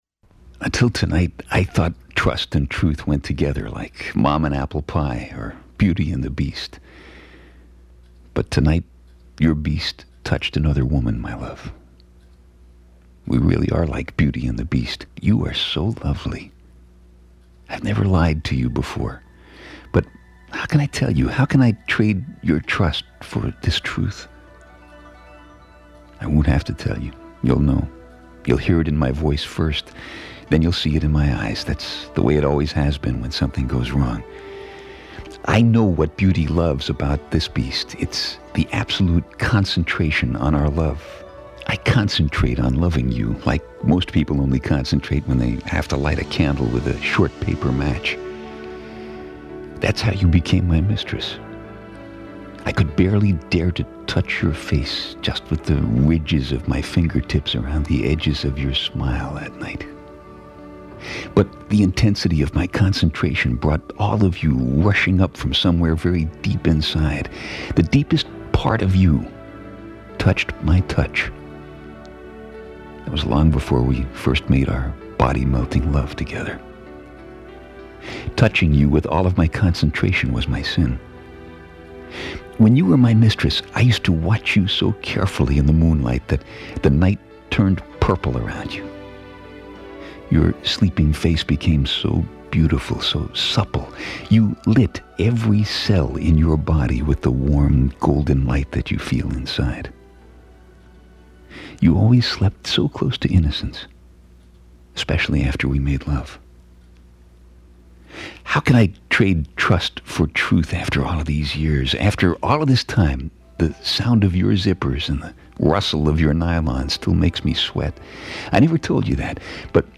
The story is told by one of America's most familiar radio and tv voices, Dick Summer.